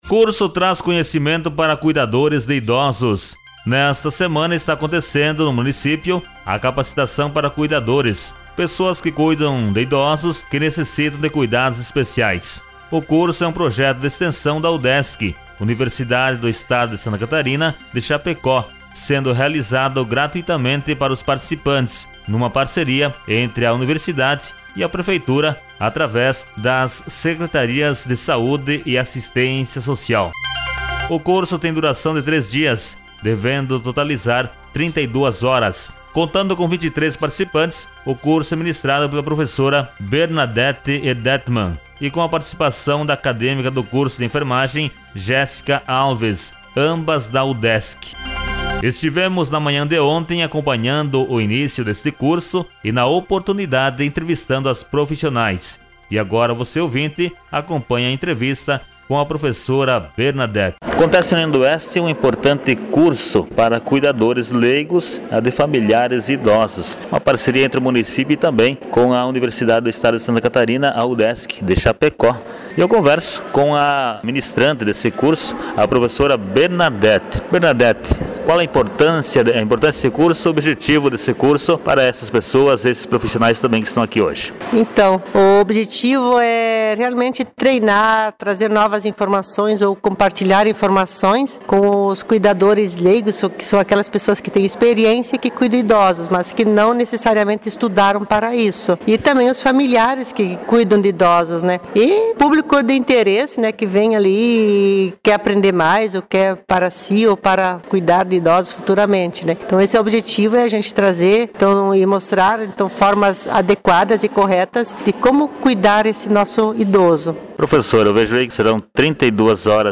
Reportagem e entrevista